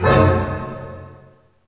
HIT ORCHD0AL.wav